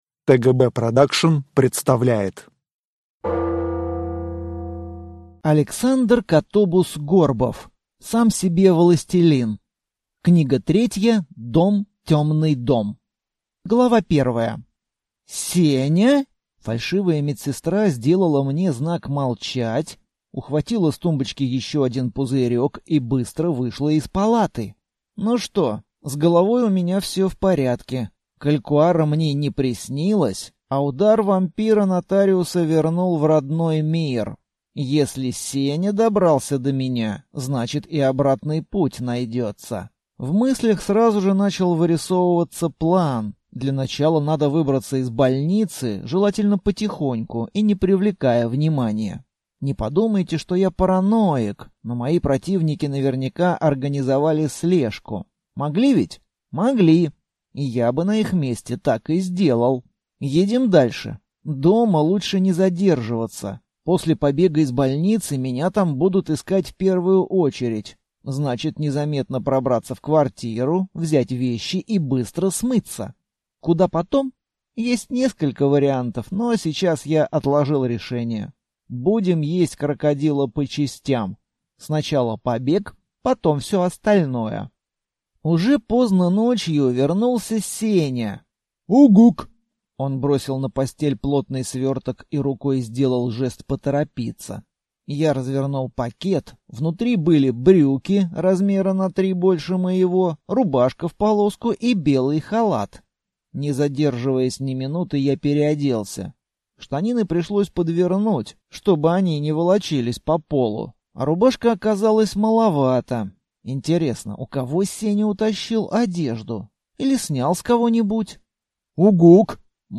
Аудиокнига Сам себе властелин. Книга 3. Дом, тёмный дом | Библиотека аудиокниг